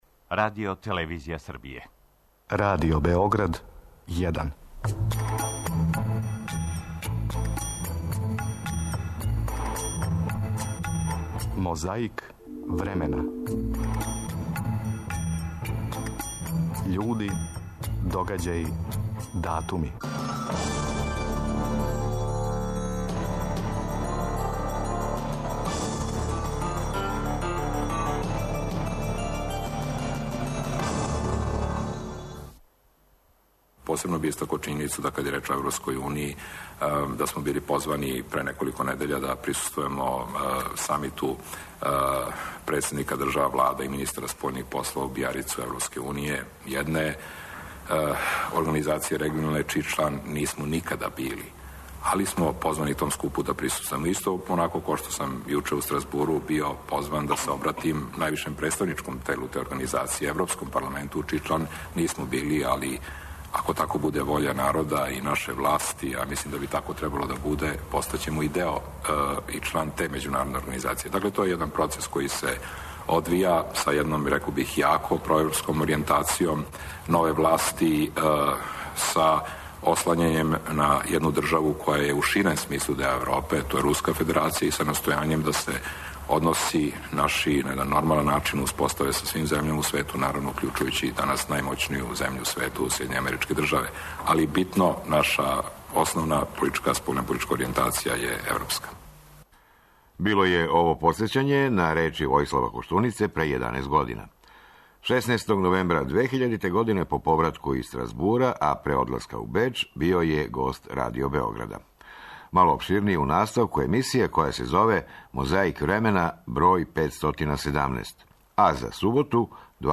И све то на таласима Радио Београда.